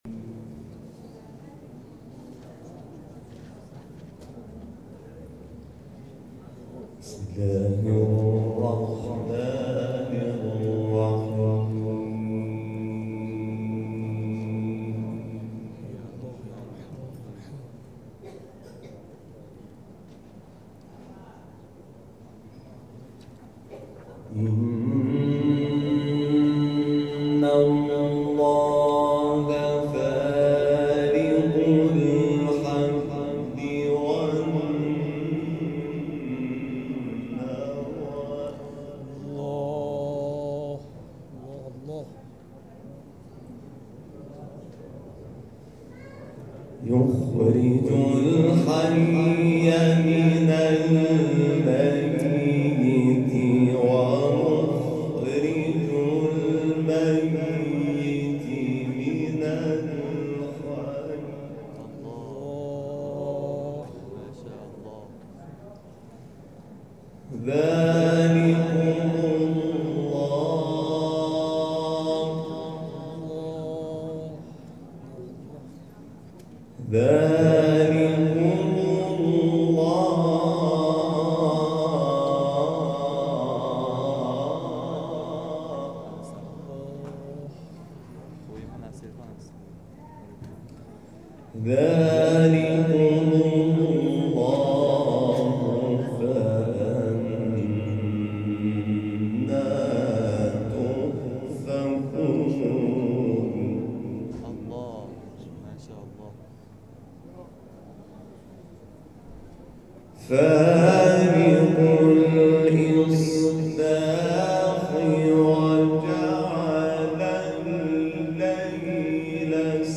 جدیدترین تلاوت حمید شاکرنژاد + دانلود
گروه فعالیت‌های قرآنی: محفل انس با قرآن کریم، شب گذشته، چهارم تیرماه در مسجد امام حسین(ع) بهشهر با حضور حمید شاکرنژاد برگزار شد.